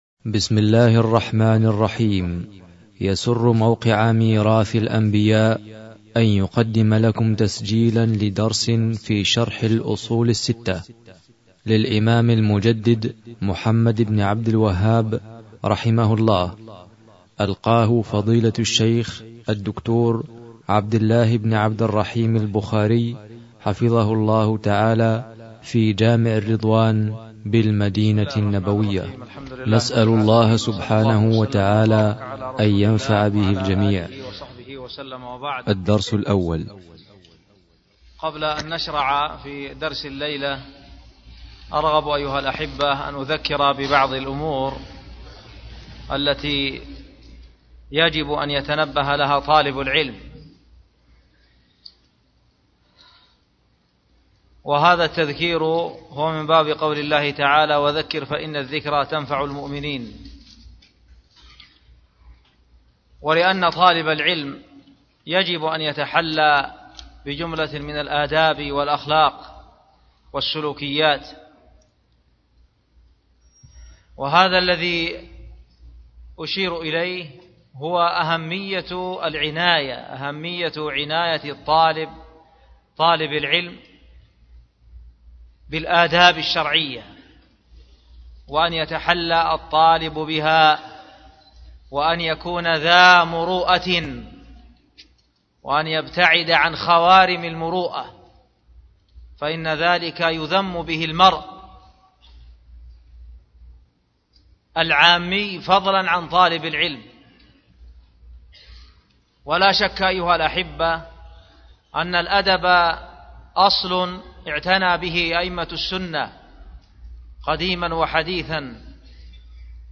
شرح